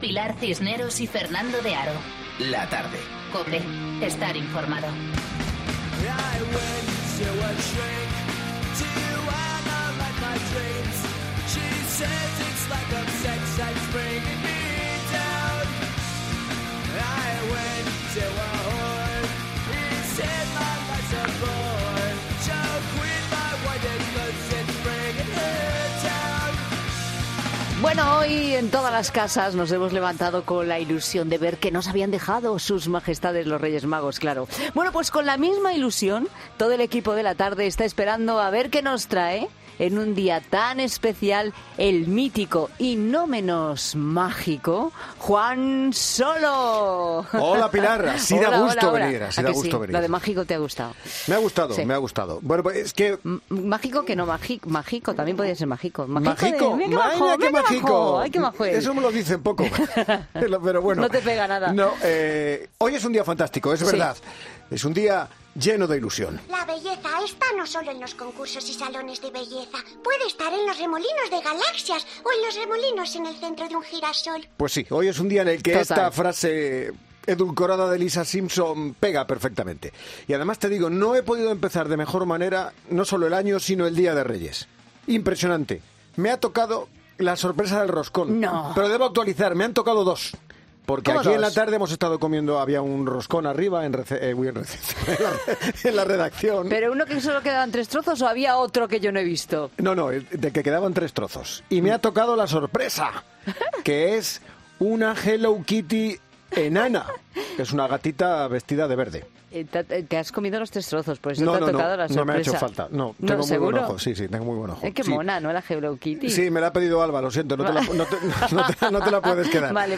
AUDIO: La particular versión divertida de la actualidad de la mano del mítico cómico y colaborador de La Tarde